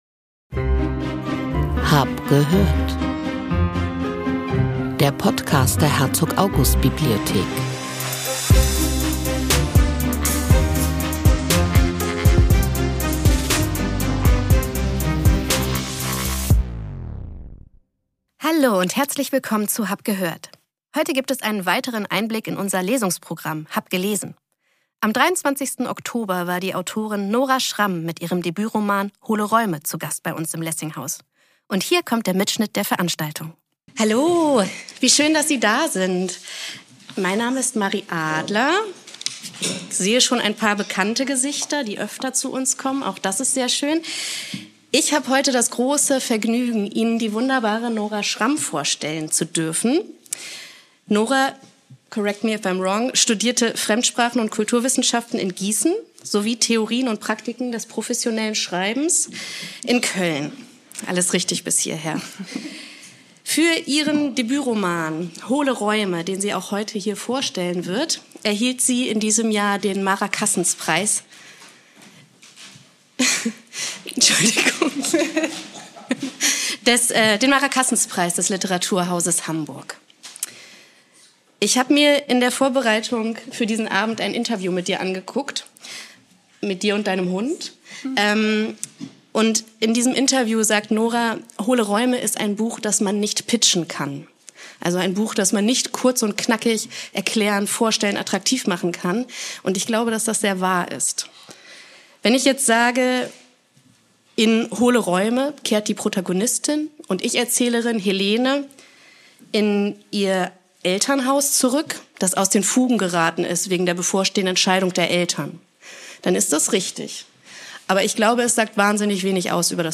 Die Lesung fand am 23. Oktober 2025 im Gartensaal des Lessinghauses der Herzog August Bibliothek statt.